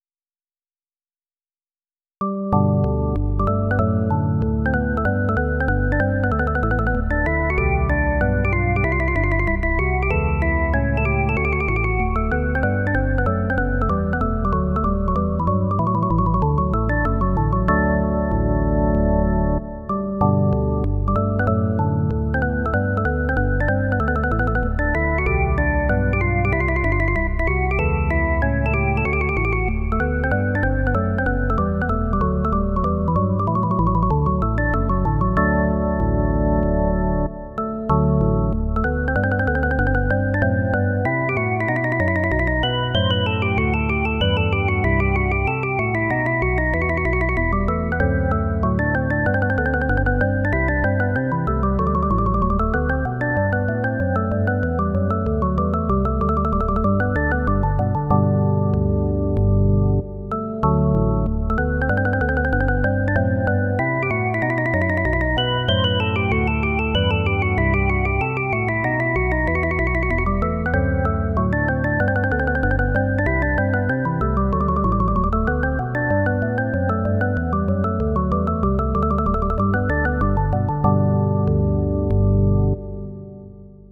Barroco
teclado
suite
piano
clave
danza